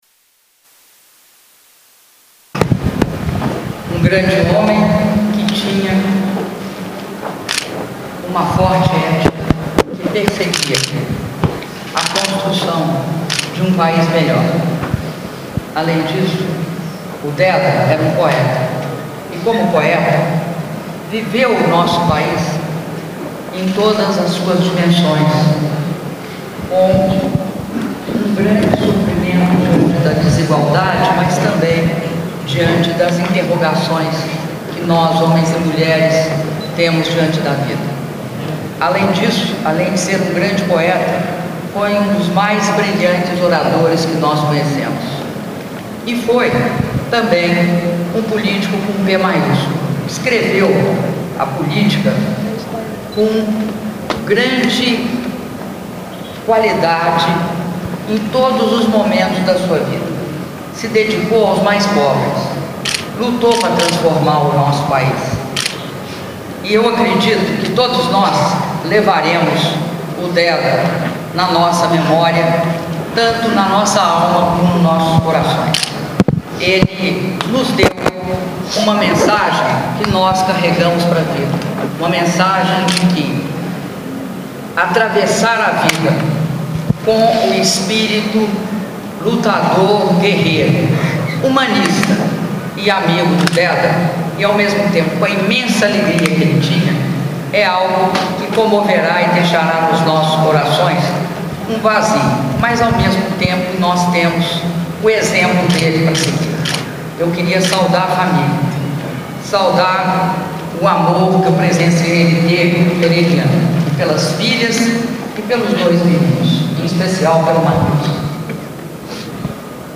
Áudio das palavras da Presidenta da República, Dilma Rousseff, durante o velório do governador de Sergipe, Marcelo Déda